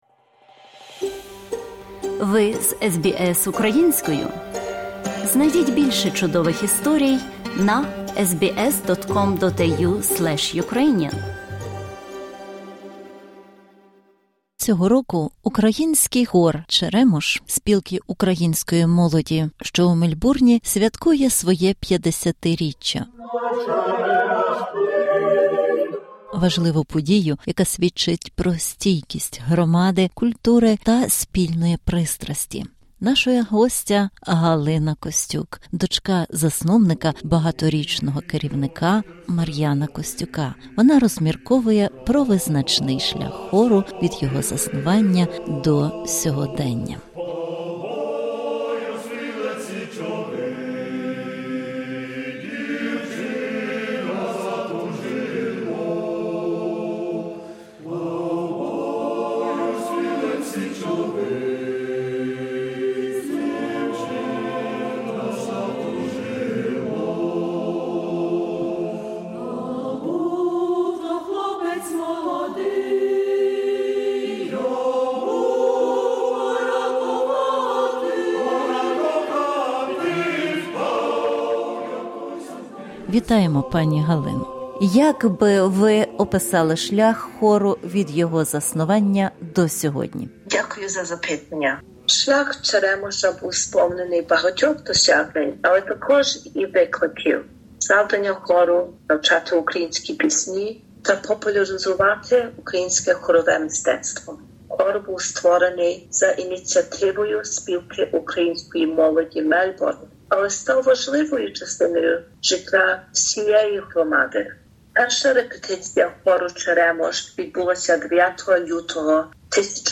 У цьому інтерв'ю